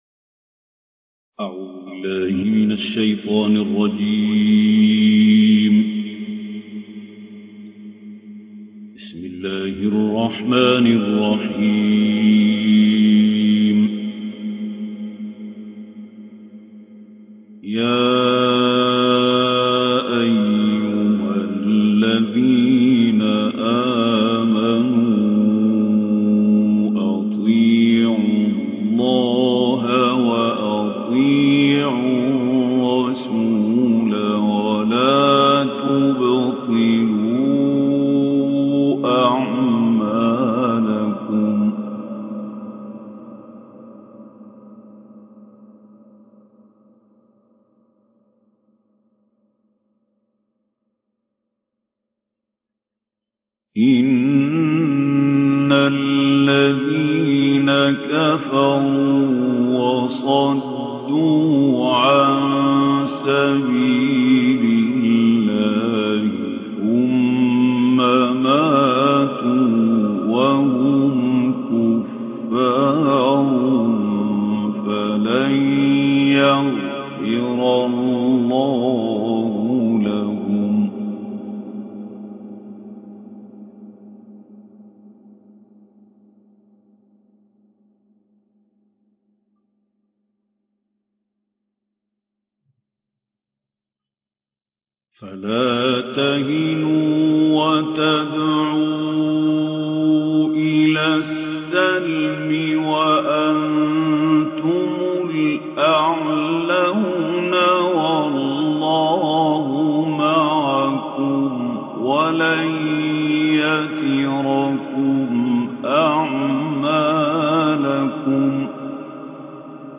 Reciter Mahmoud Khaleel El Hussary